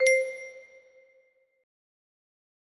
Thanks music box melody